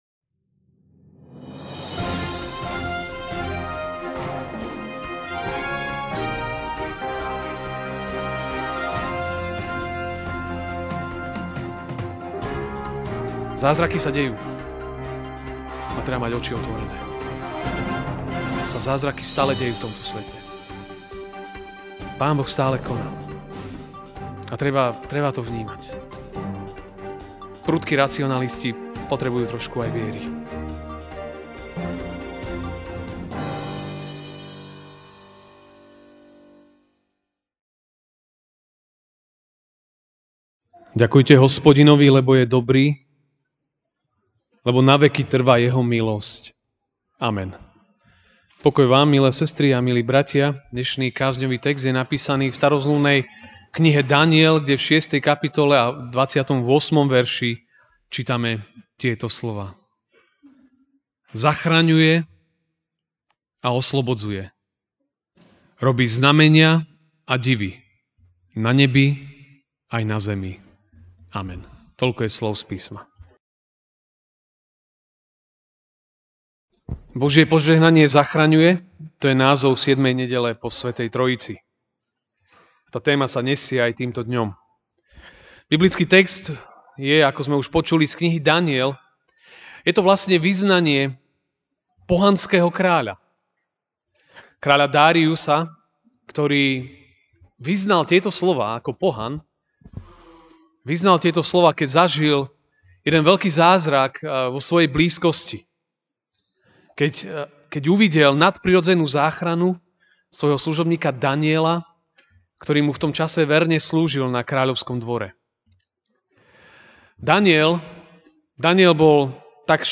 Ranná kázeň: V rukách toho, ktorý predivne zachraňuje (Daniel 6, 28) Zachraňuje a oslobodzuje, robí znamenia a divy na nebi aj na zemi.